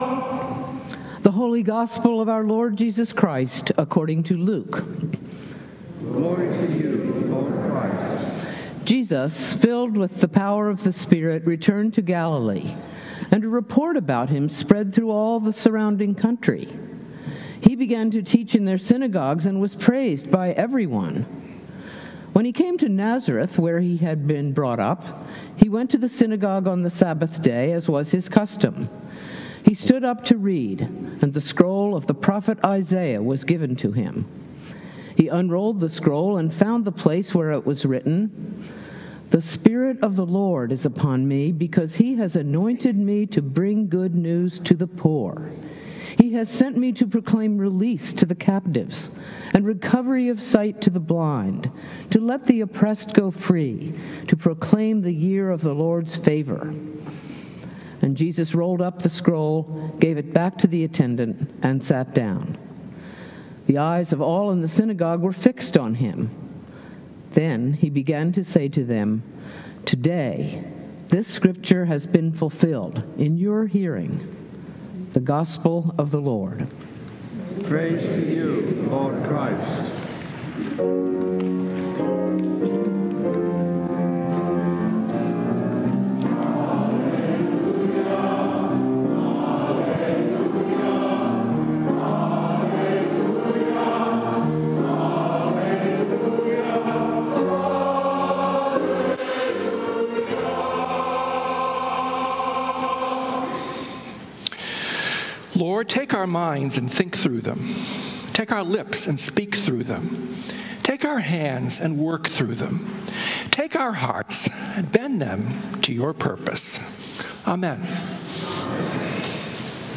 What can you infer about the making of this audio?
Sermons from St. Columba's in Washington, D.C. “I Have No Need of You”?